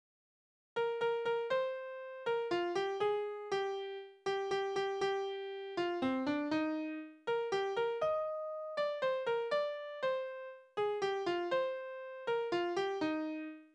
Balladen: Gianette und der Schiffer
Tonart: Es-Dur
Taktart: 3/4, 4/4
Tonumfang: kleine Dezime
Besetzung: vokal
Anmerkung: zwei Takte stehen im 4/4-Takt, um den Auftakt zu ermöglichen